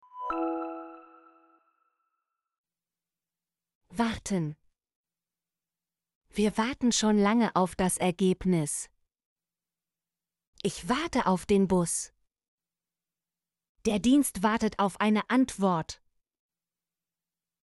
warten - Example Sentences & Pronunciation, German Frequency List